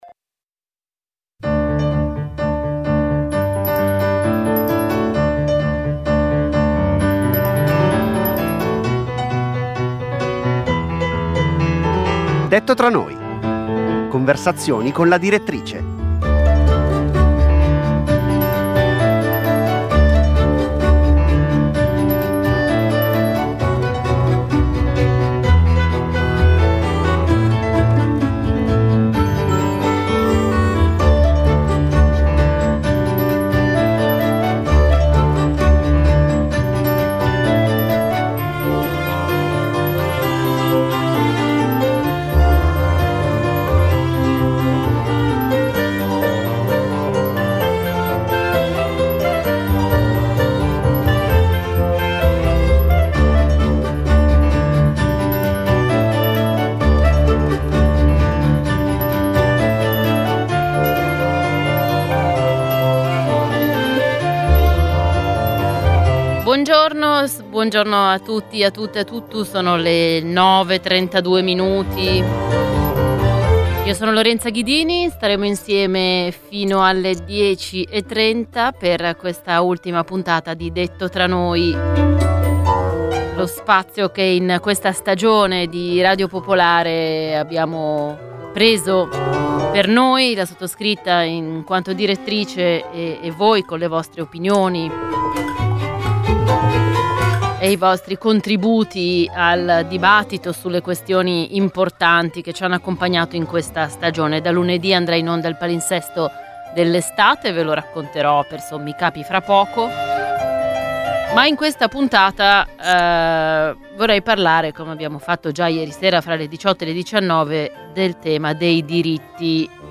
Conversazioni con la direttrice.